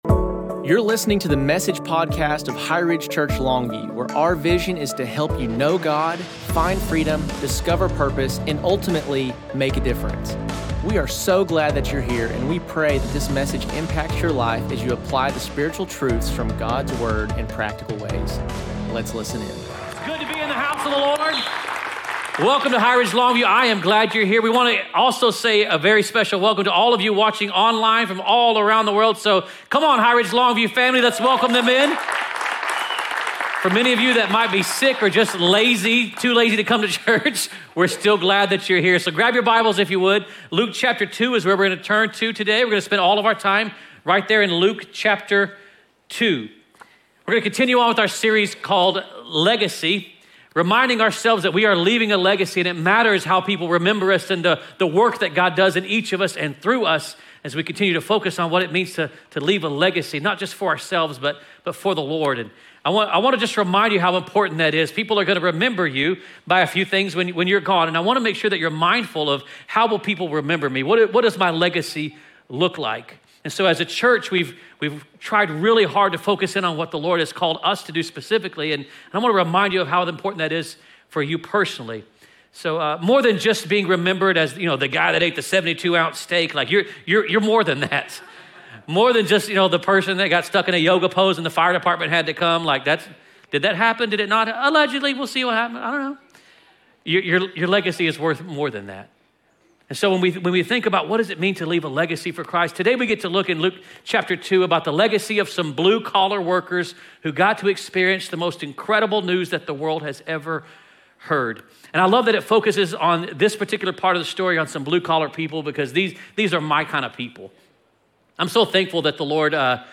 HighRidge Church Longview Legacy - Part 2 Dec 14 2025 | 00:45:45 1x 00:00 / 00:45:45 Subscribe Share Apple Podcasts Spotify Overcast RSS Feed Share Link Embed